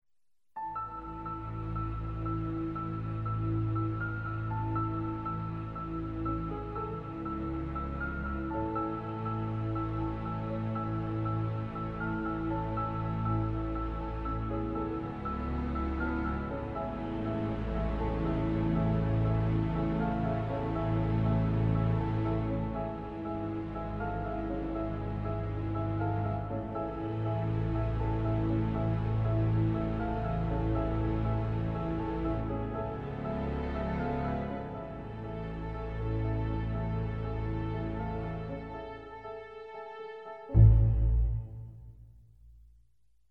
Short incidental piece, for piano and strings.